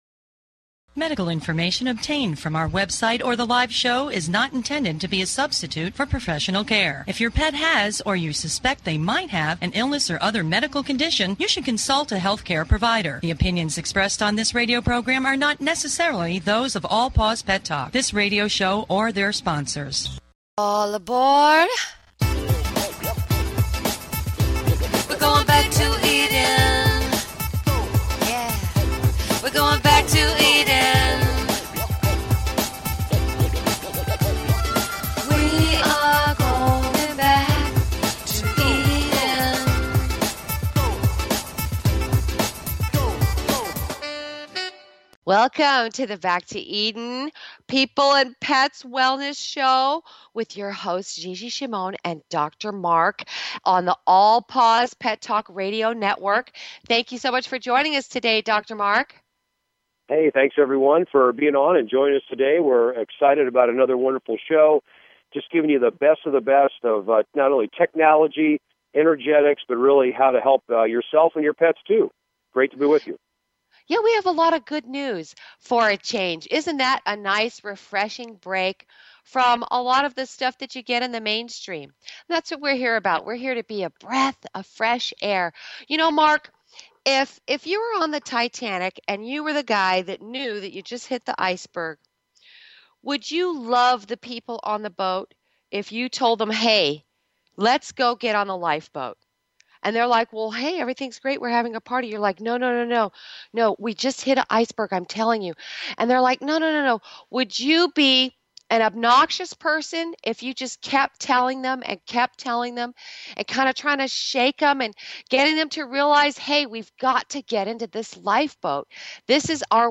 Talk Show Episode
Interview the 2nd half